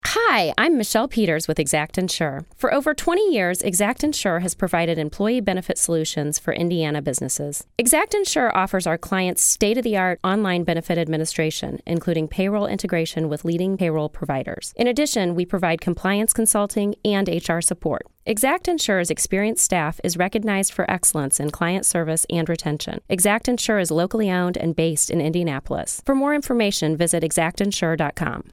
You can hear us in the afternoons on the radio at FM 97.5 or AM 1260!